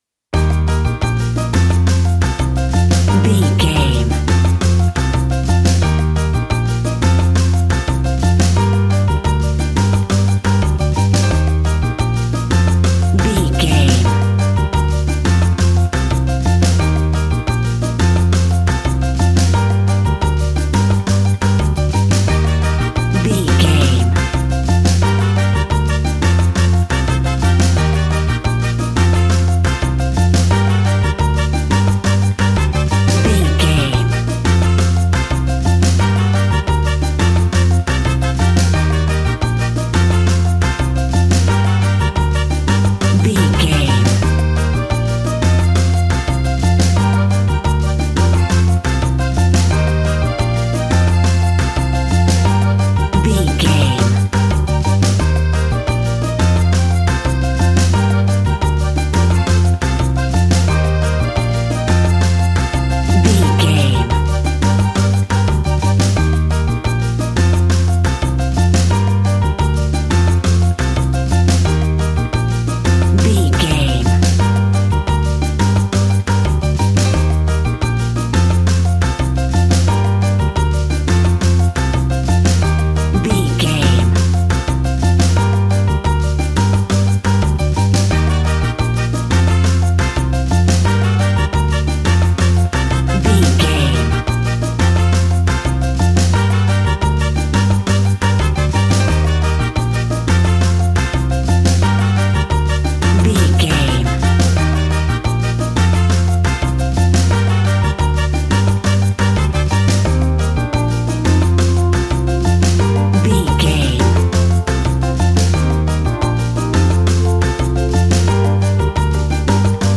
An exotic and colorful piece of Espanic and Latin music.
Aeolian/Minor
romantic
maracas
percussion spanish guitar
latin guitar